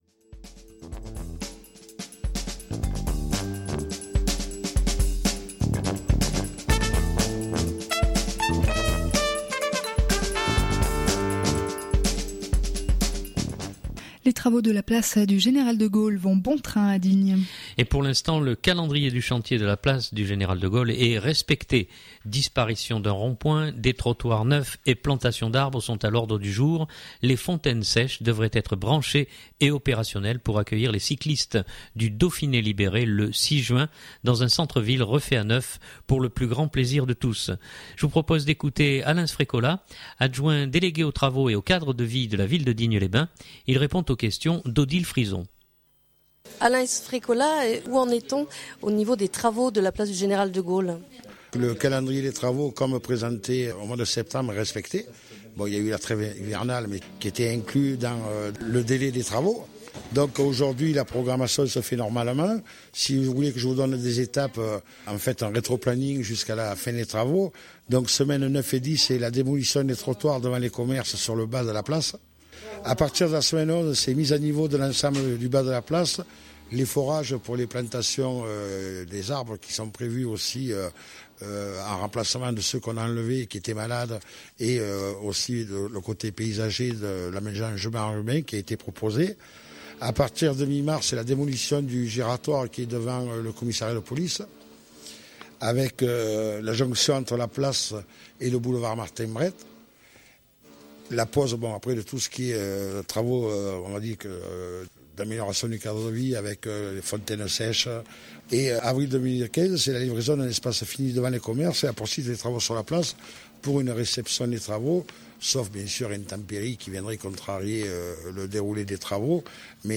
Je vous propose d’écouter Alain Sfrecola Adjoint délégué aux travaux et au cadre de vie de la ville de Digne-les-Bains.